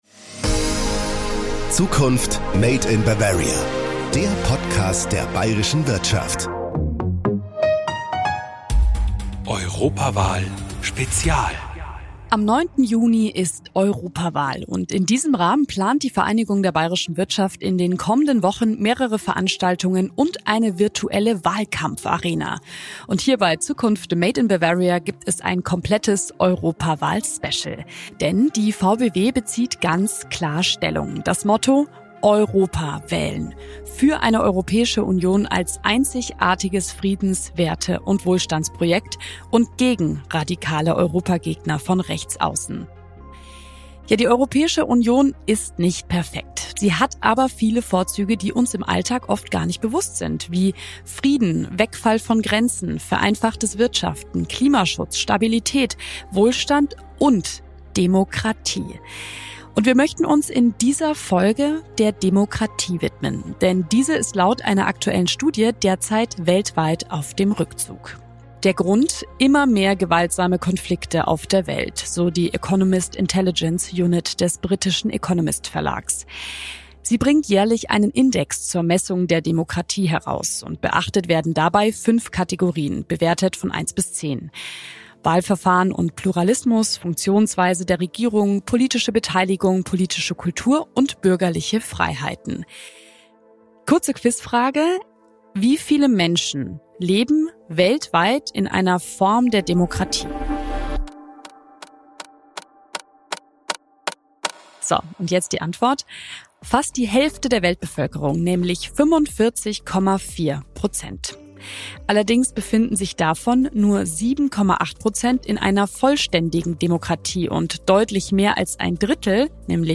Die beiden sprechen über die Herausforderungen, vor denen die Demokratie in Deutschland und Europa derzeit steht, und welche Gefahren ihr drohen.